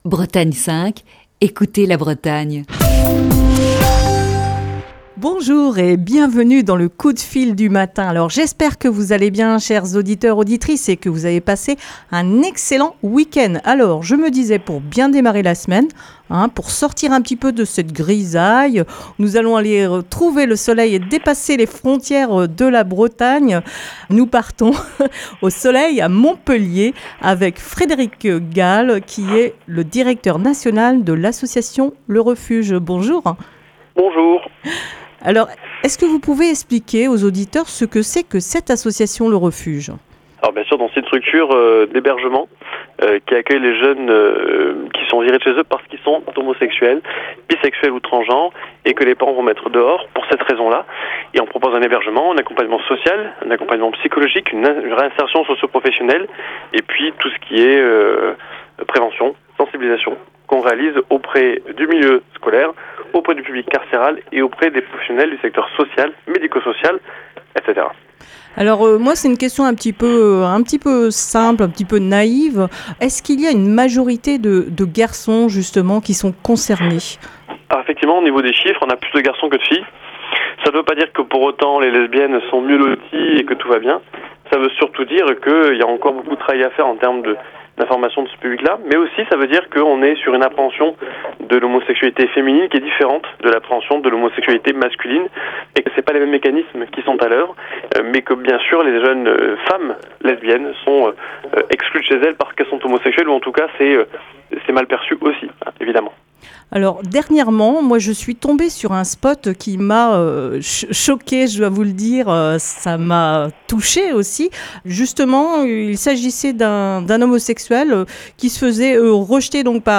Émission du 3 février 2020.